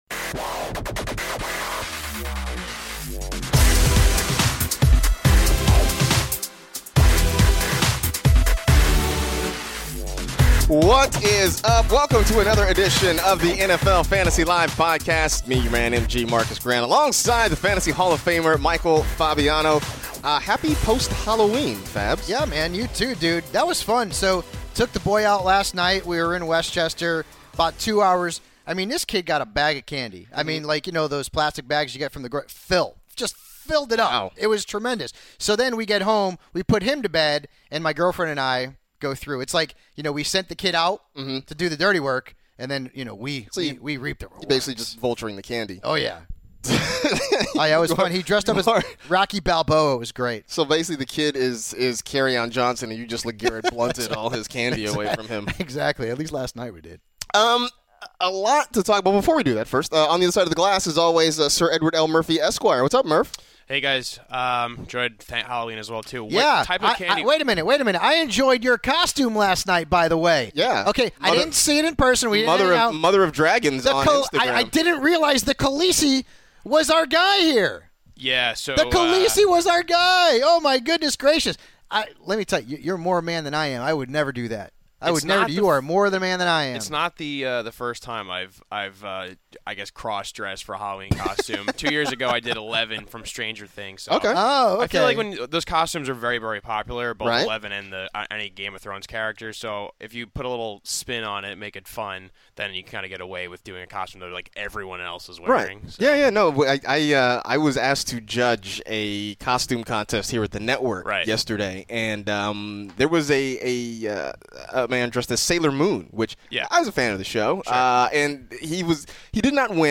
in studio to preview everything fantasy football related for Week 9!